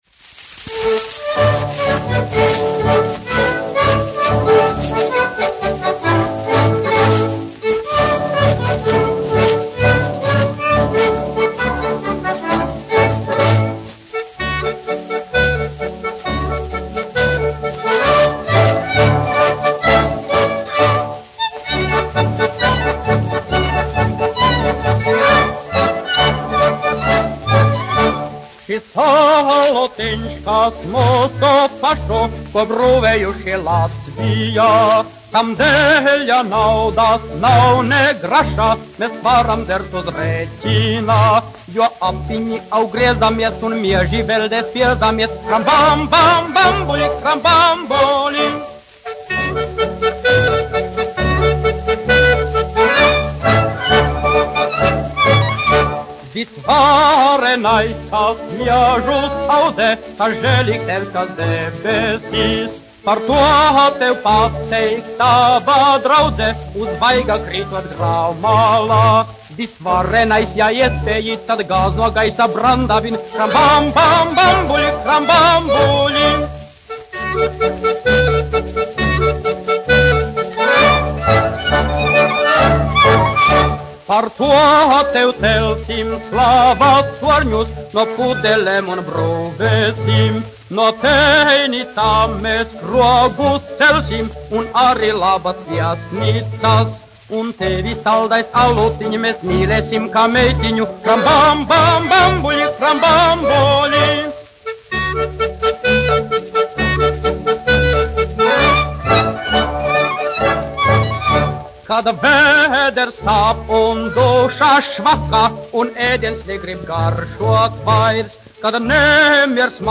Krambambulis : studentu dziesma
Poriņš, Alfrēds, 1902-1969, dziedātājs
1 skpl. : analogs, 78 apgr/min, mono ; 25 cm
Populārā mūzika -- Latvija
Latvijas vēsturiskie šellaka skaņuplašu ieraksti (Kolekcija)